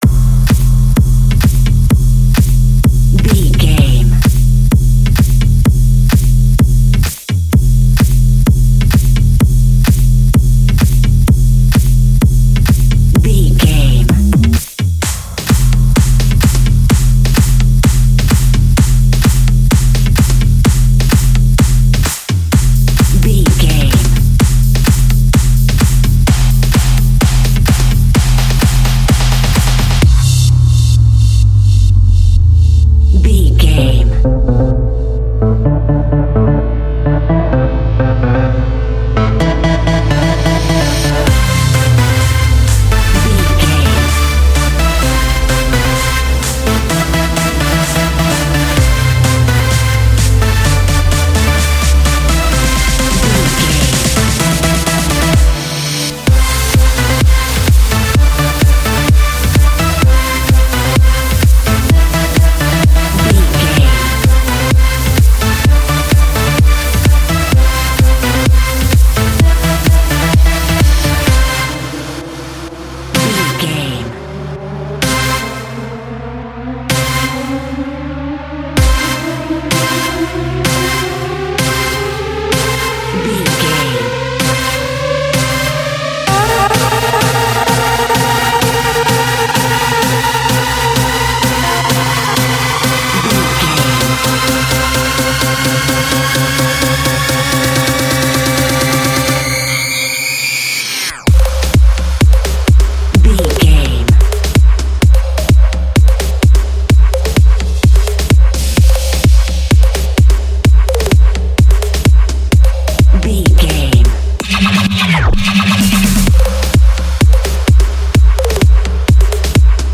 Underground Trance Club.
Aeolian/Minor
heavy
energetic
uplifting
hypnotic
industrial
drum machine
synthesiser
acid house
electronic
uptempo
synth leads
synth bass